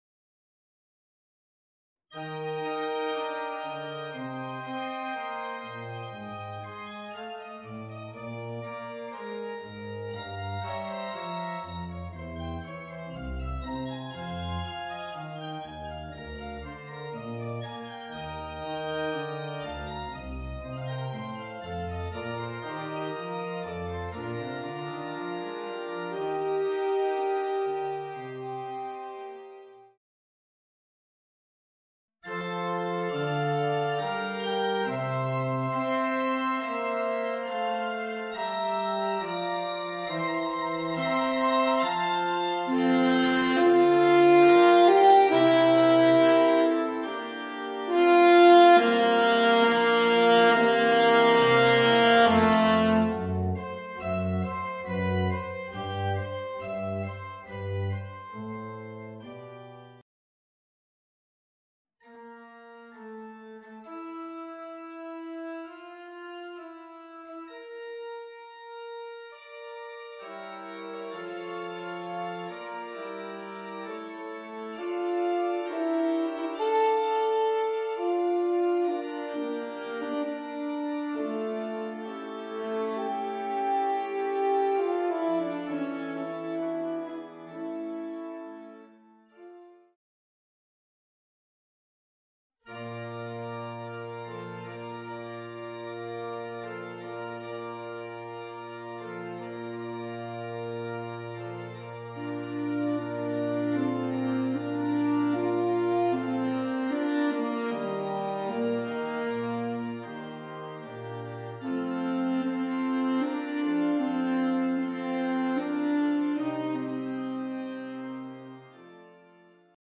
アルトサックス+ピアノ